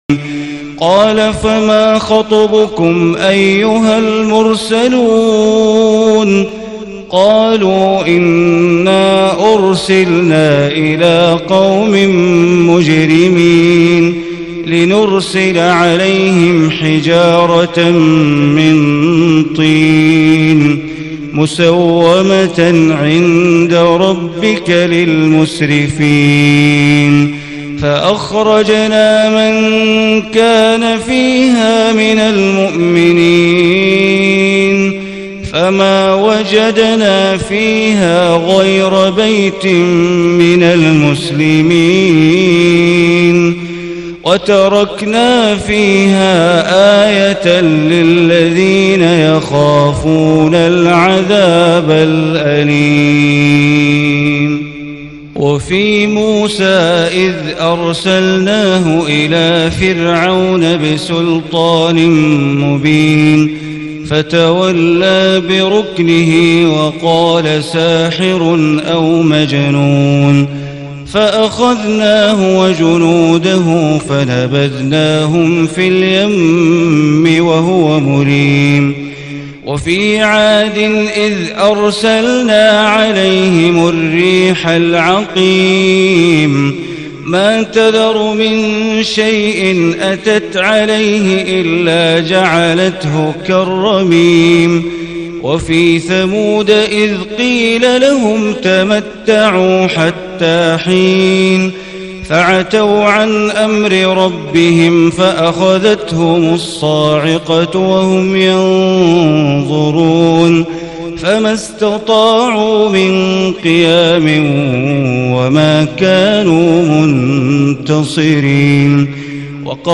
دانلود نسخه صوتی ترتیل جزء بیست و هفتم قرآن با صدای بندر بلیله
Bandar-Baleela-Quran-Juz-27.mp3